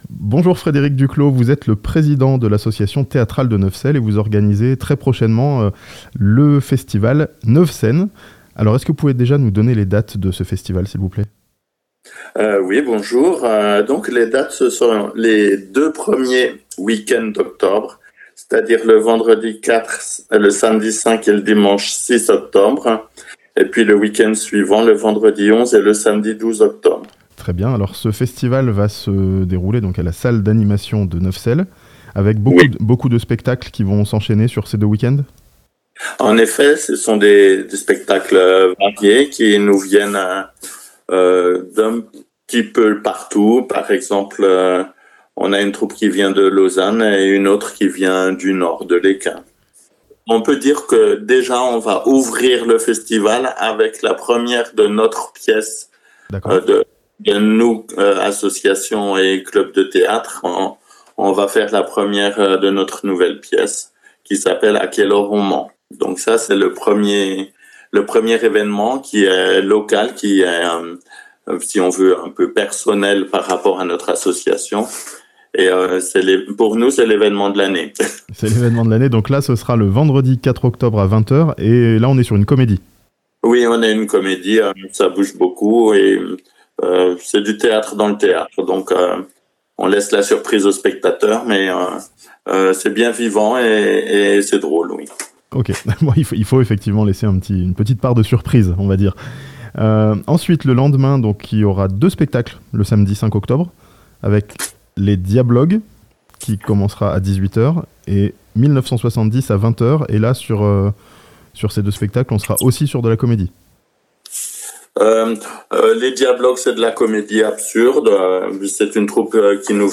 Un festival de théâtre, ces deux prochains weekends, à Neuvecelle (interview)